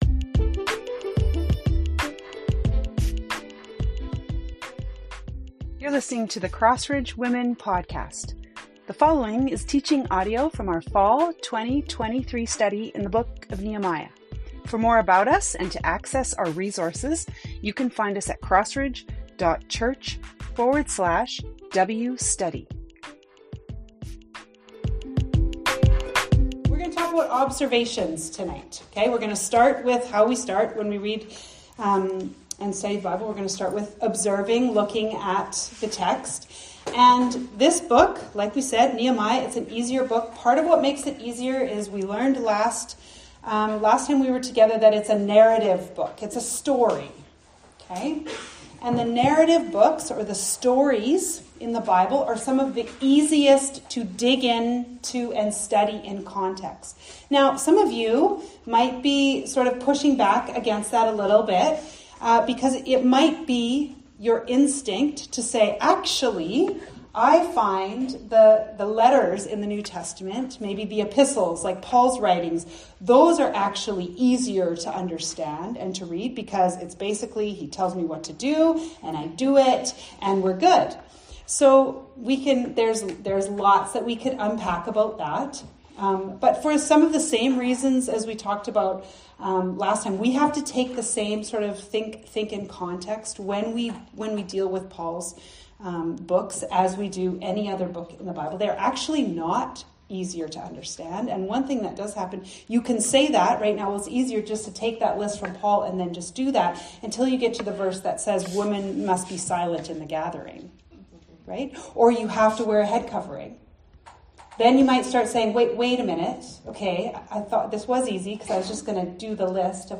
The work of Nehemiah and The People of God. Audio from our large group discussion and teaching time in chapters 1-3.